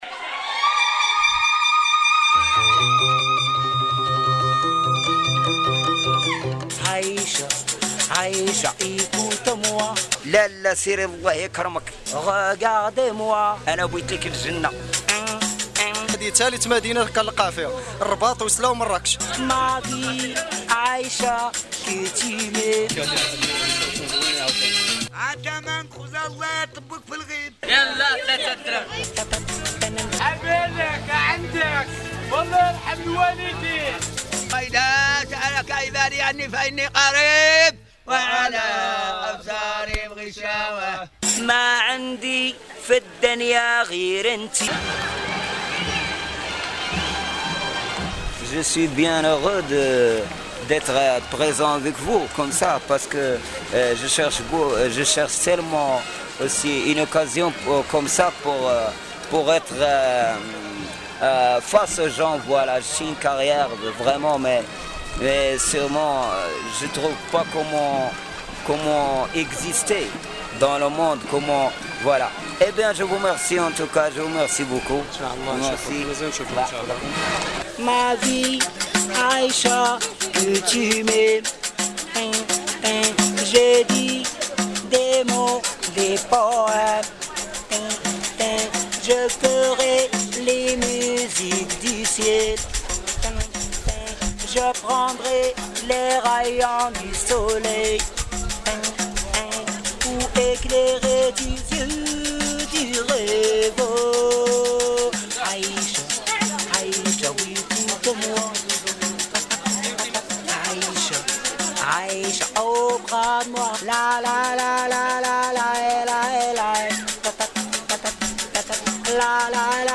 Cette carte je la dédie à tous les artistes de la rue au Maroc. Ce sont eux que nous allons entendre, captés au fil du hasard dans les rues de Rabat, Fès, Meknès ou Marrakech. Ces artistes, musiciens et chanteurs, offrent aux passants de véritables spectacles, et pourtant leur statuts est celui de mendiants. Observez bien la variété de styles musicaux qui représente un patrimoine culturel dont ces artistes de la rue sont les authentiques conservateurs.